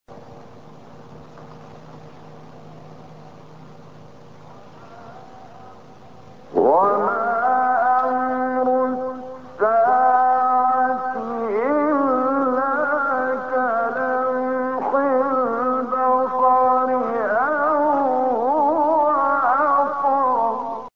گروه شبکه اجتماعی: فرازهای صوتی از کامل یوسف البهتیمی که در مقام بیات اجرا شده است، می‌شنوید.